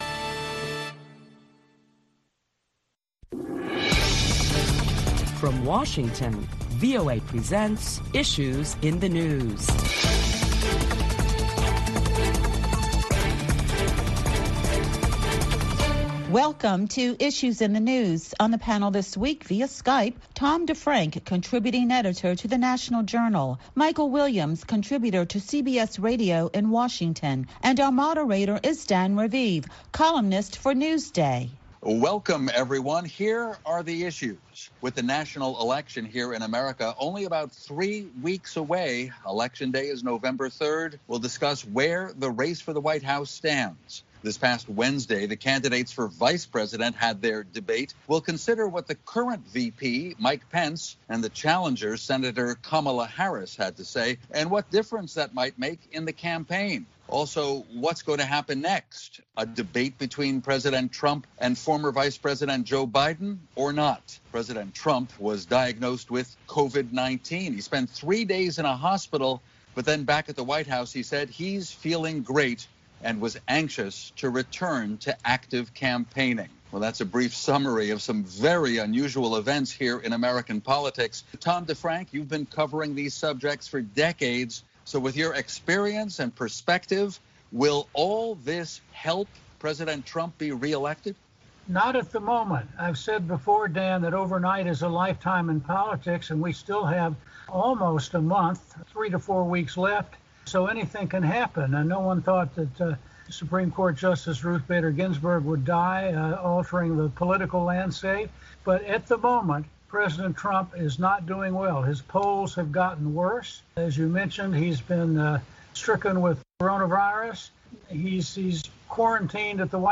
A panel of prominent Washington journalists for Issues in the News deliberate the top stories of the week including an outbreak of coronavirus at the White House that infected President Donald Trump and many others.